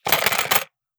Gun Sold 004.wav